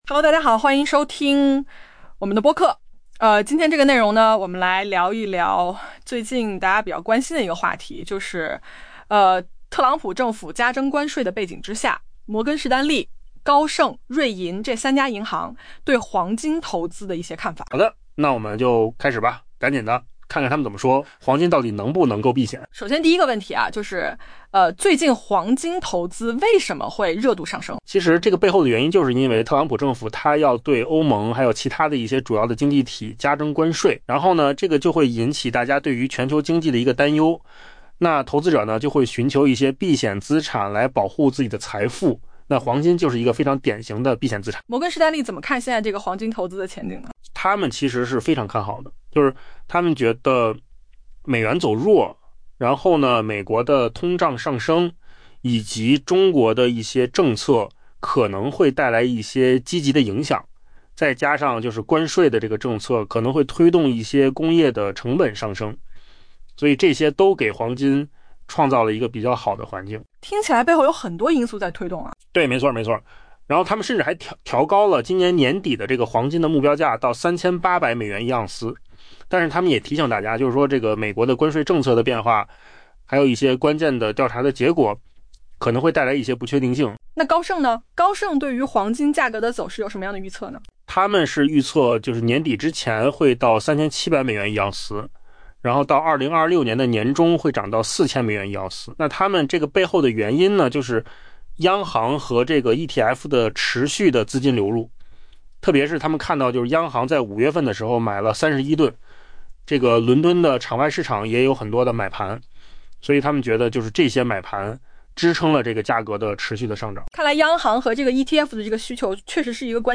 AI播客：换个方式听新闻 下载mp3